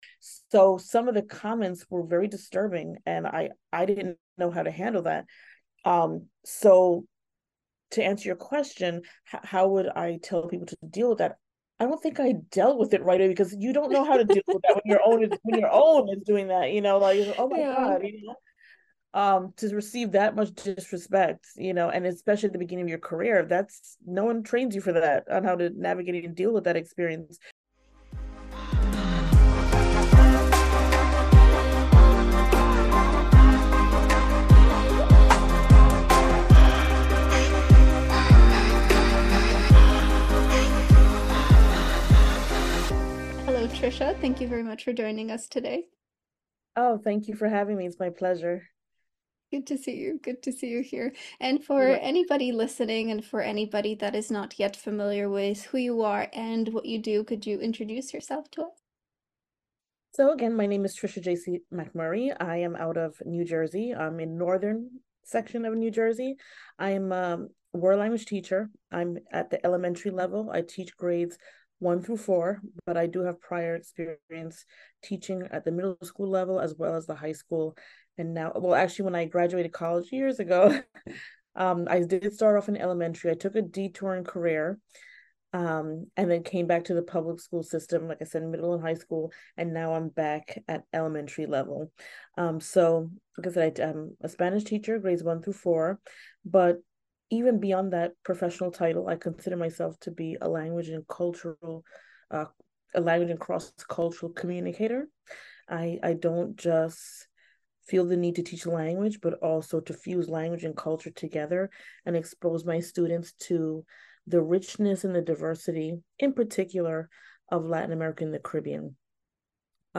With language teachers and learners as guests, they share their top study tips, methods for staying motivated while learning a new language, as well as their key MINDSET shifts and tricks to stay positive during your journey.